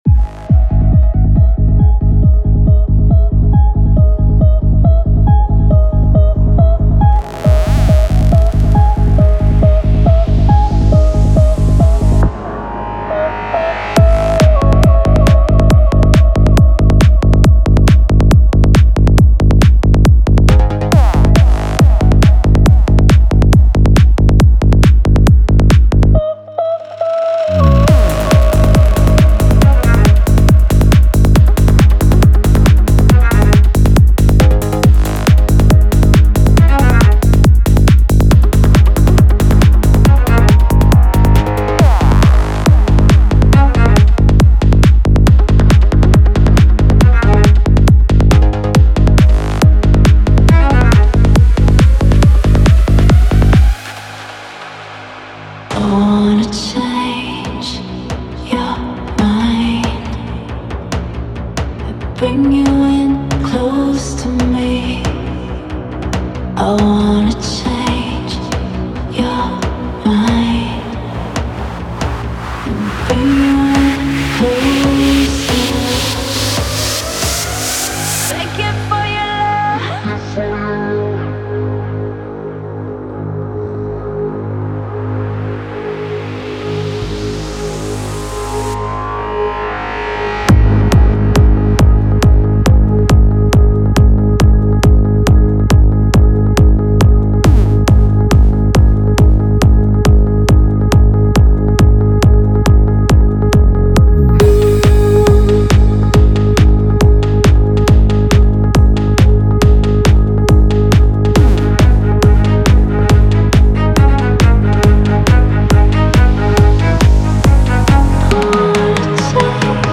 Psy-Trance Trance
Key & BPM 138 - G#m